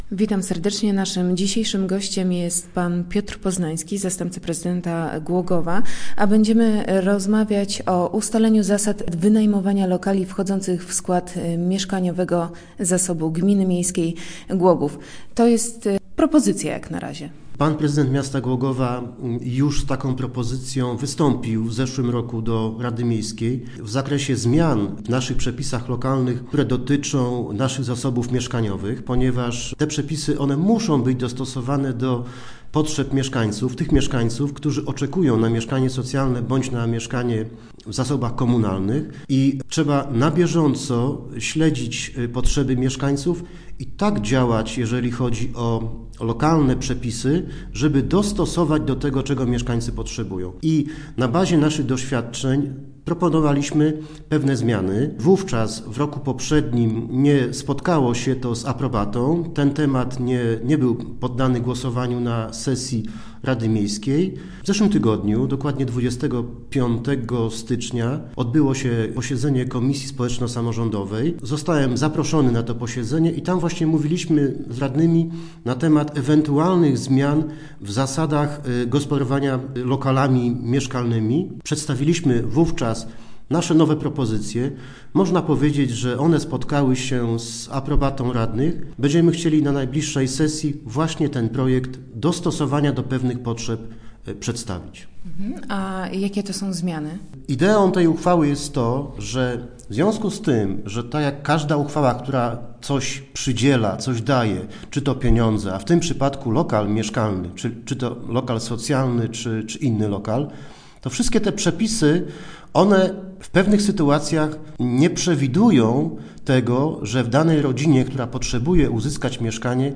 Gościem środowych Rozmów Elki był Piotr Poznański. Z zastępcą prezydenta Głogowa rozmawialiśmy o ustaleniu zasad wynajmowania lokali wchodzących w skład mieszkaniowego zasobu Gminy Miejskiej Głogów.